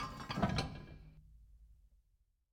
heavy-sliding-unlock.ogg